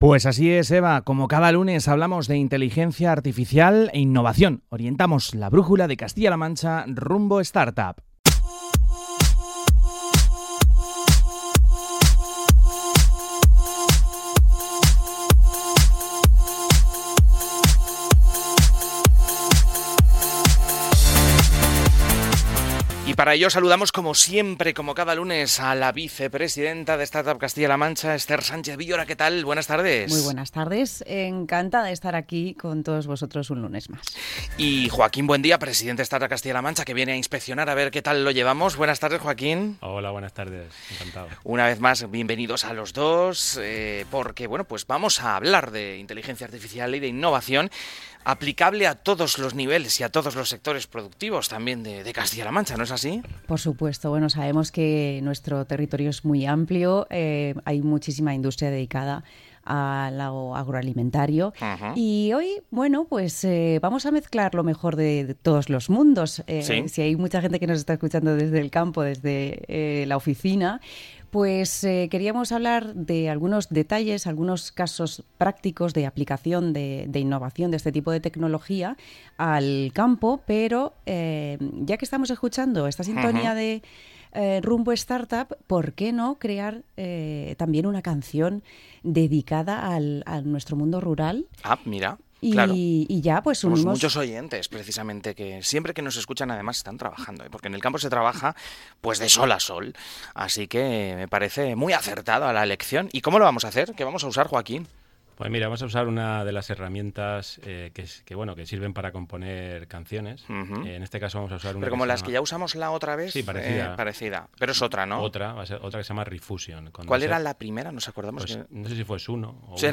¿Quién se iba a imaginar que una tarde de lunes acabaría en pleno programa de radio componiendo una canción con inteligencia artificial y ritmo pop-tecno?
No te lo pierdas: tuvieron hasta tiempo de encargar—en directo—una canción dedicada al agro con letras generadas al momento y sonidos que van desde el pop más alegre hasta el tecno cañero.